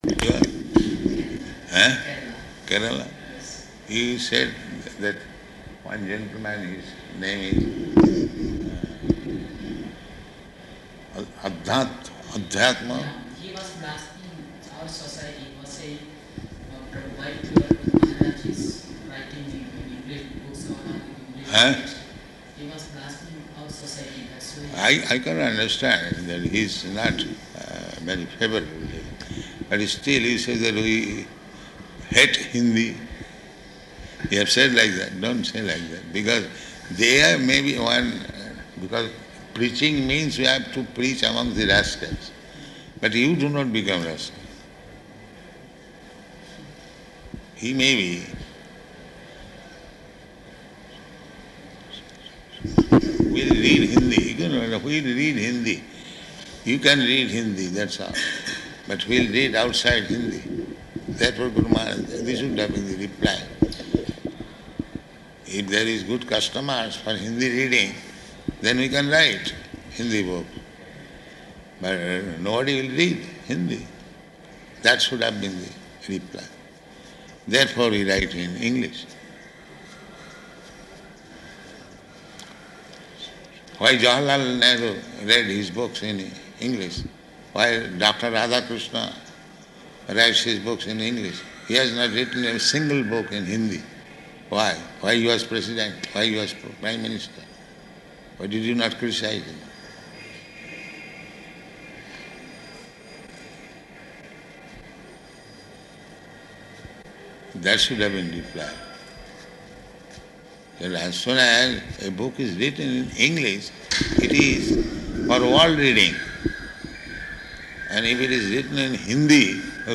Room Conversation
Room Conversation --:-- --:-- Type: Conversation Dated: April 20th 1974 Location: Hyderabad Audio file: 740420R1.HYD.mp3 Prabhupāda: ...Kerala?